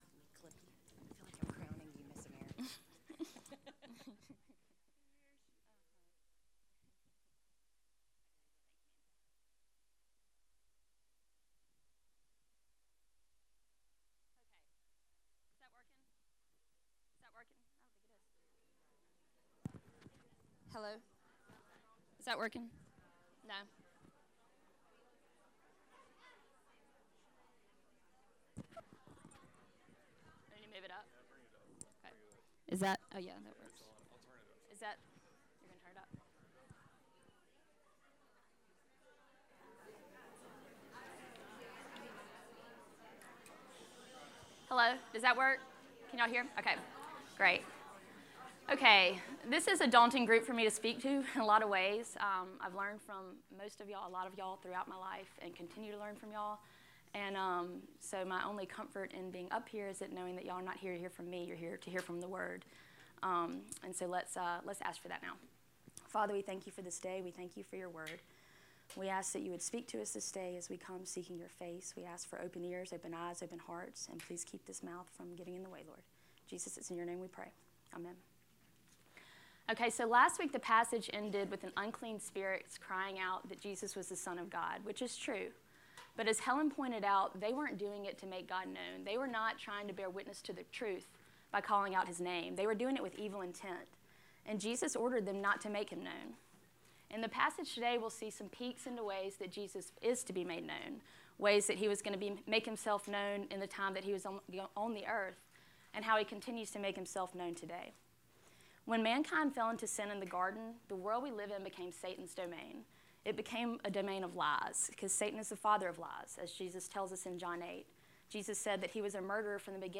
Lesson 5